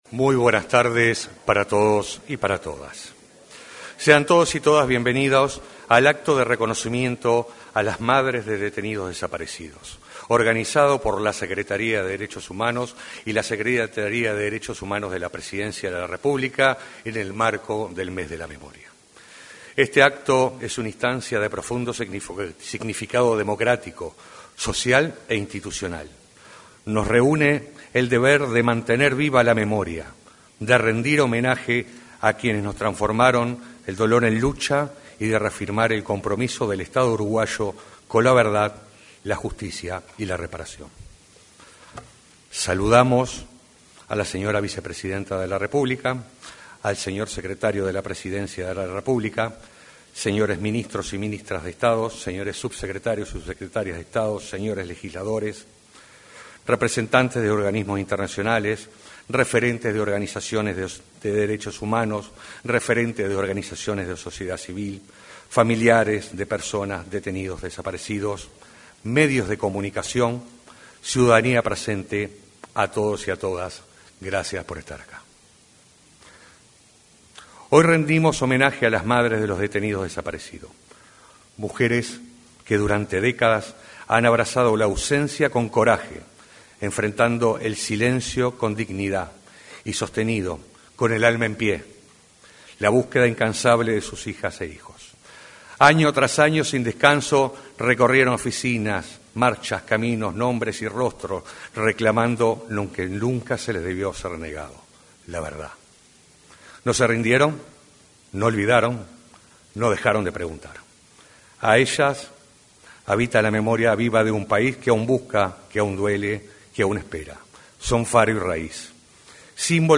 Acto de reconocimiento a las madres de detenidos desaparecidos